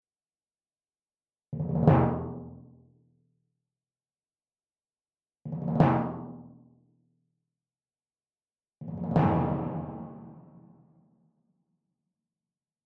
A) Timpani B only panned & and compressed
B) ...with A) and EQ
C) ...with A), B), and ConvolutionRev.
Cre_VSL_Choir-Demo-Timpani1.mp3